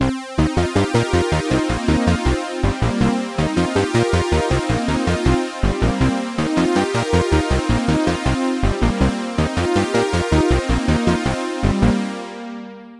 描述：80 bpm 4 bar的复古旋律合成器。
Tag: 4bars 模拟合成器 复古 试验 循环 80bpm 旋律